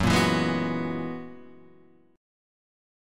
F# 11th